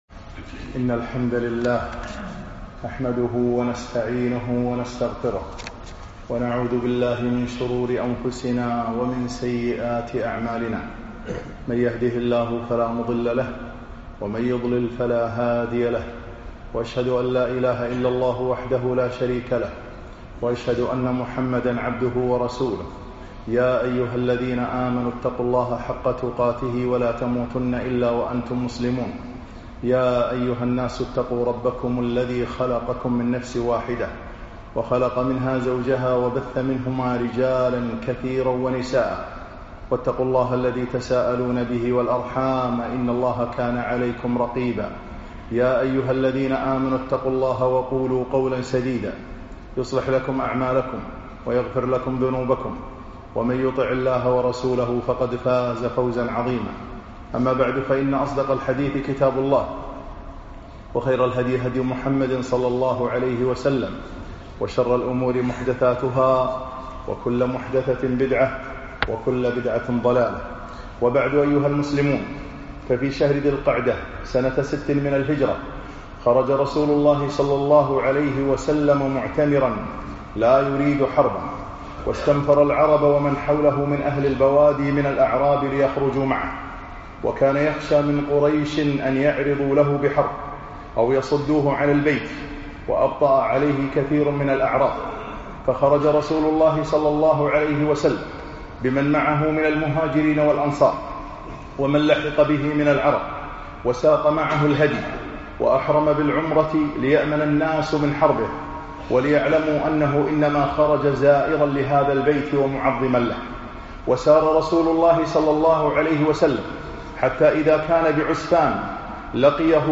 خطب السيرة النبوية 20